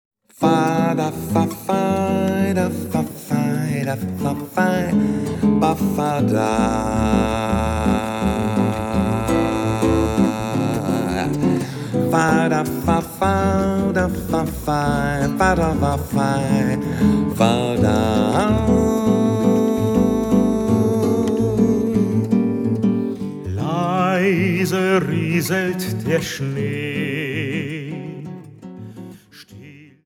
Vocal, Gitarre
Bariton
Jazz trifft klassischen Gesang